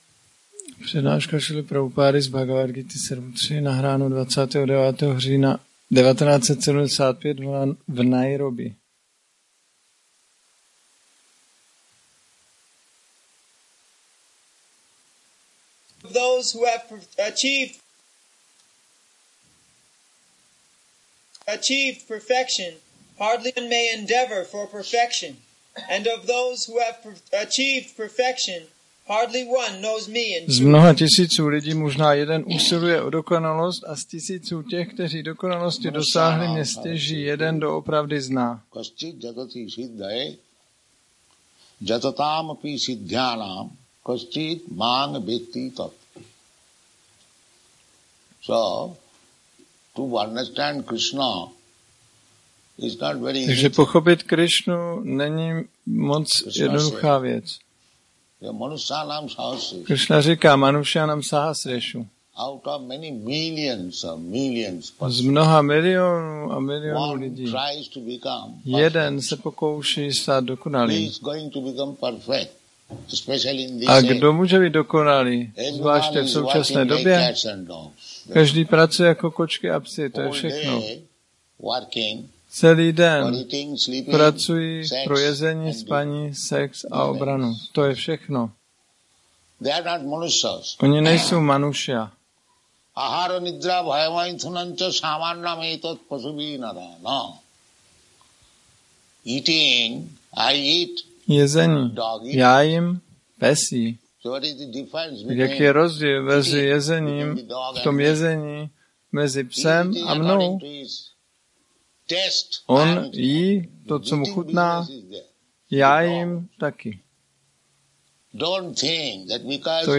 1975-10-29-ACPP Šríla Prabhupáda – Přednáška BG-7.3 Nairobi